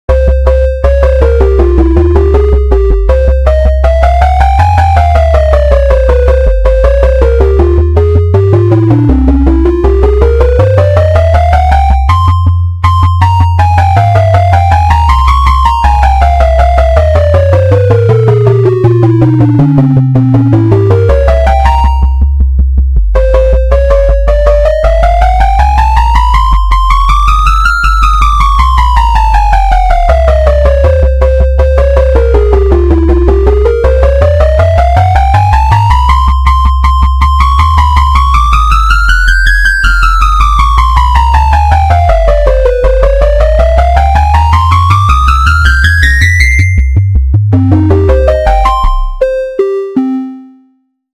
RPGの戦闘など。
BPM160